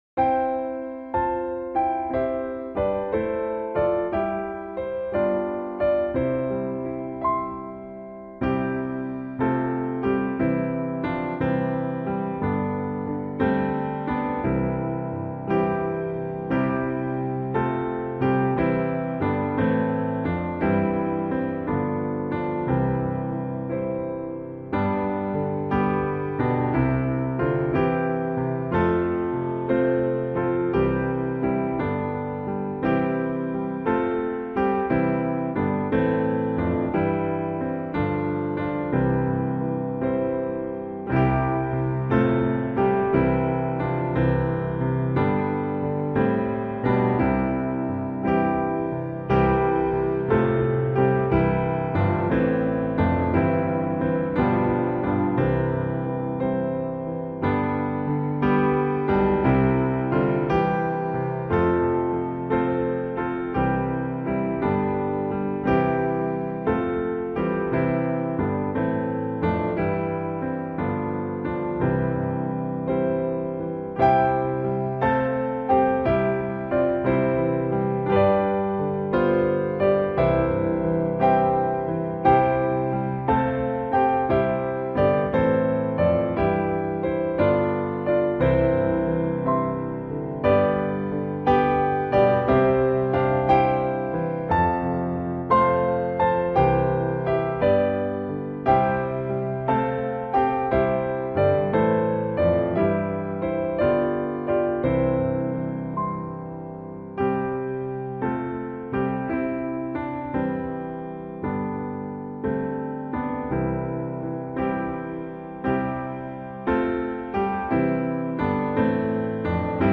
piano instrumental hymn